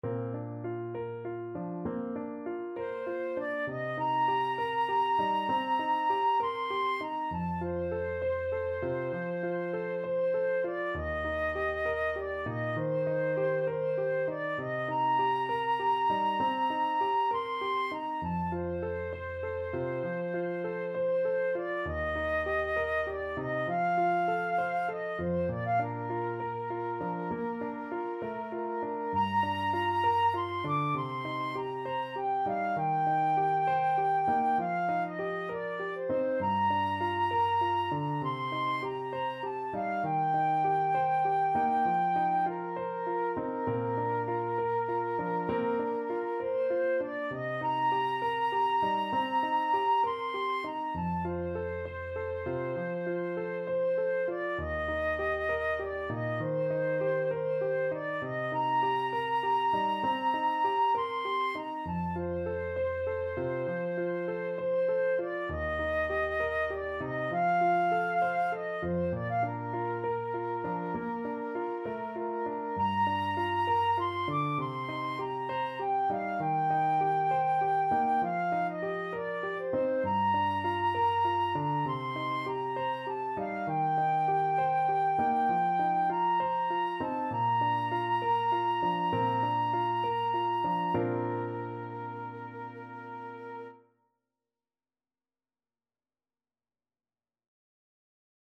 6/8 (View more 6/8 Music)
Classical (View more Classical Flute Music)